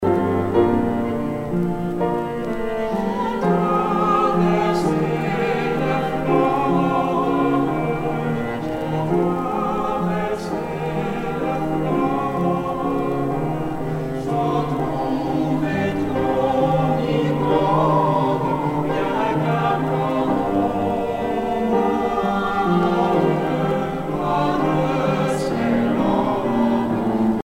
Genre énumérative
Franco-Allemande de Paris (chorale)